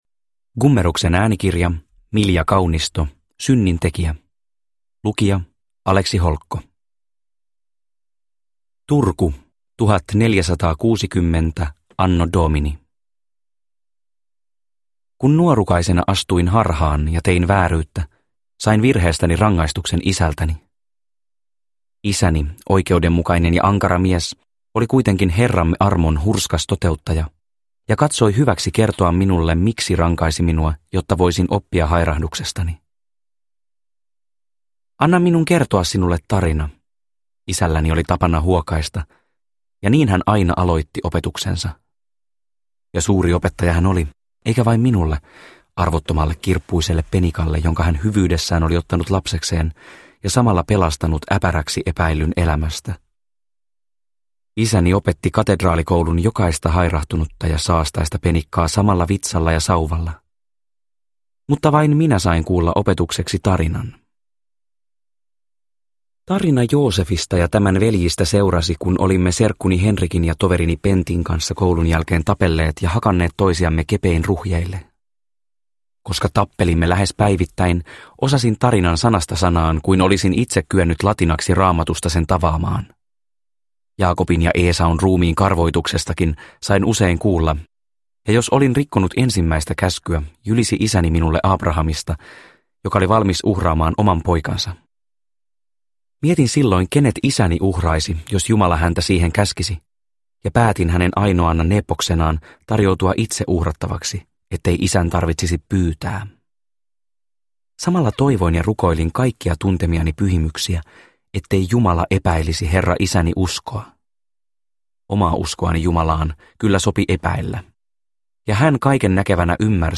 Synnintekijä – Ljudbok – Laddas ner